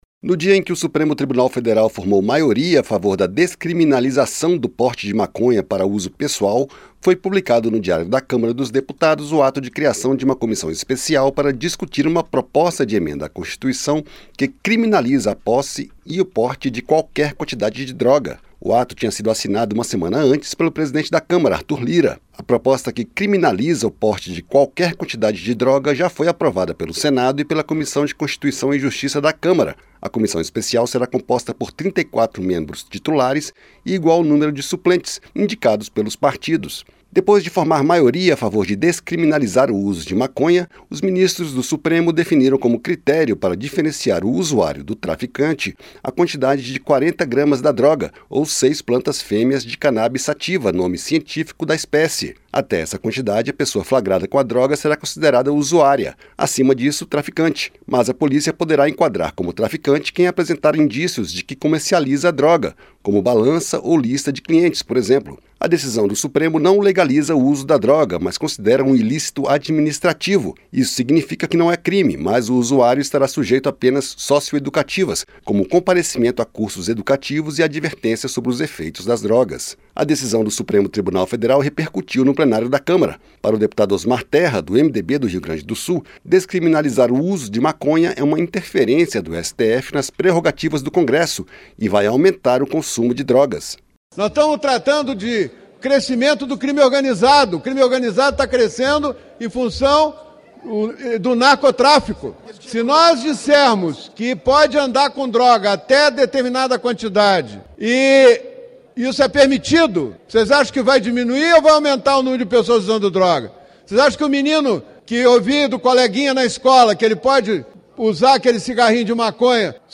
DECISÃO DO STF SOBRE O PORTE DE DROGAS DIVIDE DEPUTADOS, E A CÂMARA CRIOU UMA COMISSÃO PARA ANALISAR PROPOSTA DE EMENDA À CONSTITUIÇÃO QUE CRIMINALIZA O PORTE DE QUALQUER QUANTIDADE DE DROGAS. O REPÓRTER